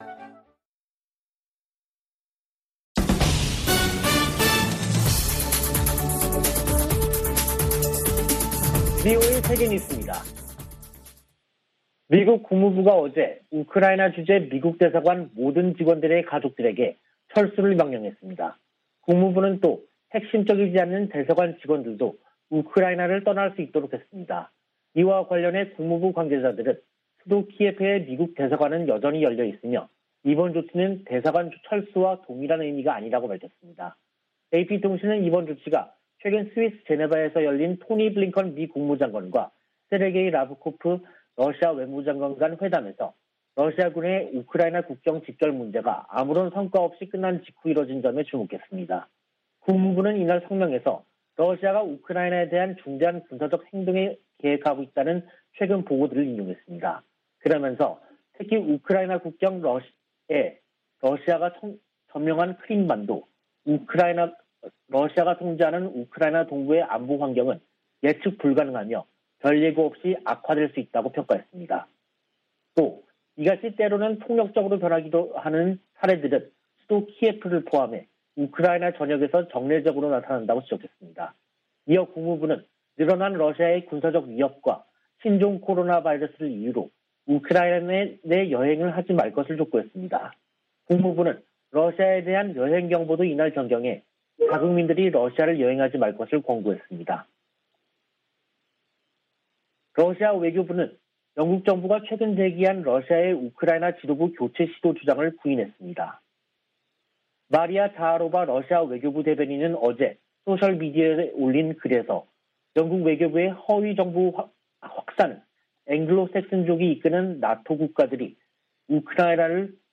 VOA 한국어 간판 뉴스 프로그램 '뉴스 투데이', 2022년 1월 24일 2부 방송입니다. 미-일 화상 정상 회담에서 북한의 잇따른 탄도미사일 시험 발사를 규탄하고 긴밀한 공조를 다짐했습니다. 미 국무부는 핵과 대륙간탄도미사일 실험 재개 의지로 해석된 북한의 최근 발표와 관련해 외교와 압박을 병행하겠다는 원칙을 확인했습니다. 미 국방부는 북한의 무기실험 재개 시사에 우려를 나타내면서도 외교적 관여 기조에는 변화가 없다고 밝혔습니다.